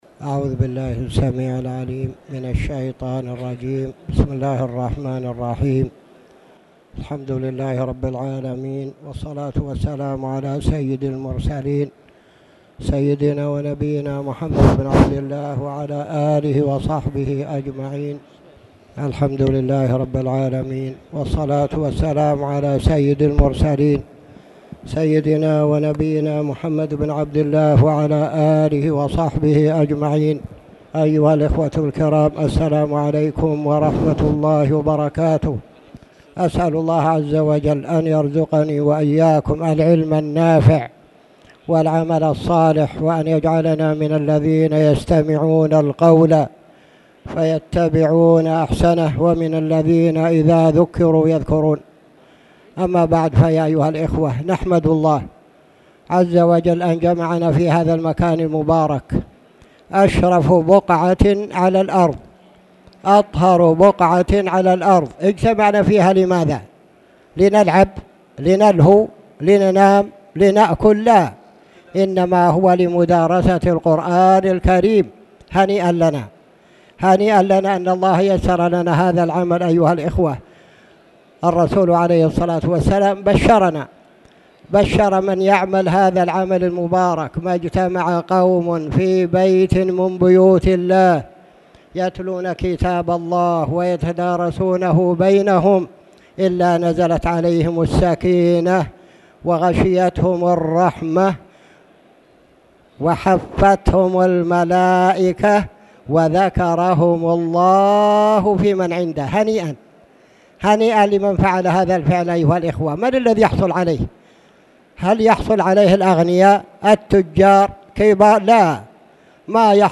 تاريخ النشر ١٥ جمادى الأولى ١٤٣٨ هـ المكان: المسجد الحرام الشيخ